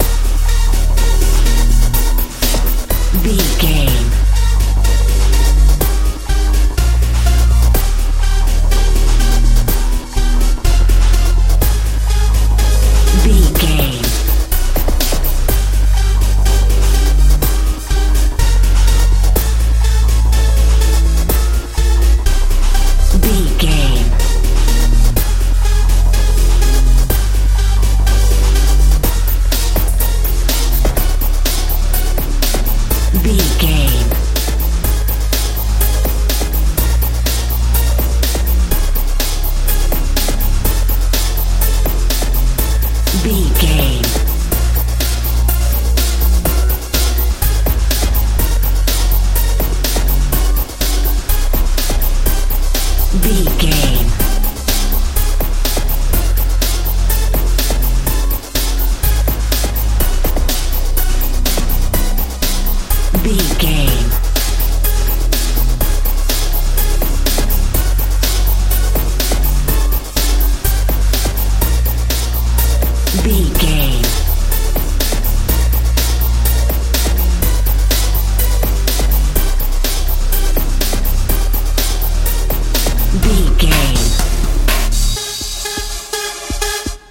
techno
Aeolian/Minor
E♭
mystical
powerful
synthesiser
bass guitar
drums
bouncy
futuristic
industrial
hard